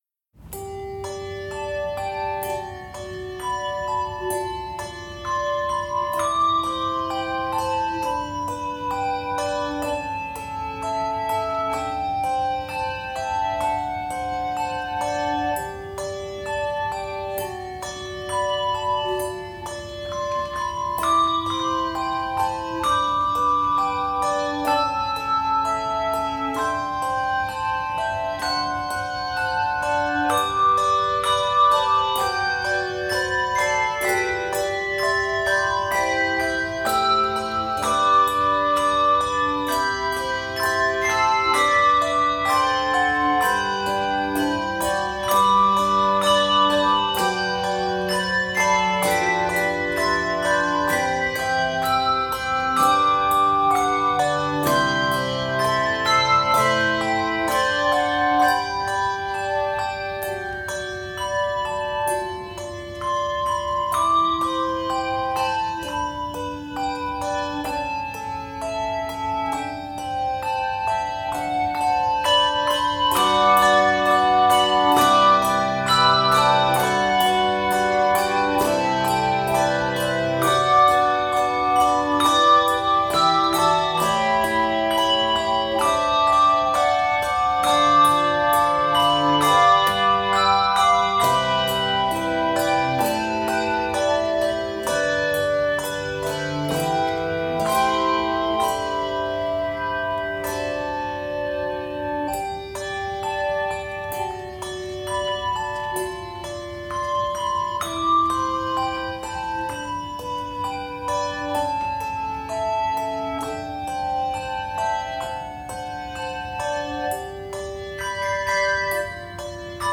This tranquil, flowing arrangement
Keys of G Major and Bb Major.